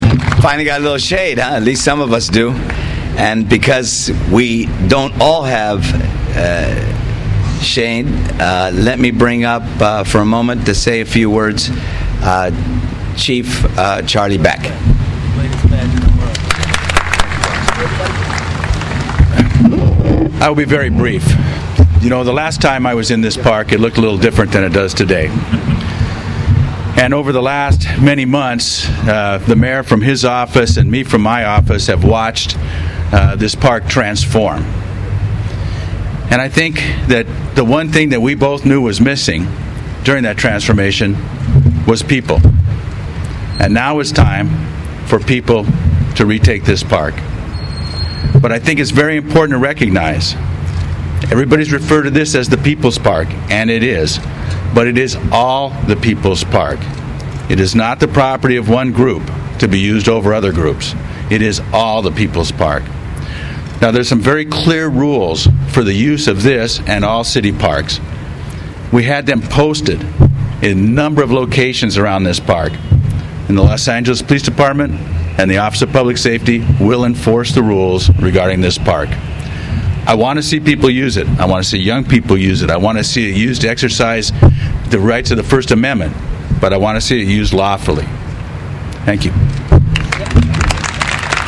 Chief Charlie Beck and city officials celebrate opening of restored and renovated City Hall Park
city-hall-park-opening-1.mp3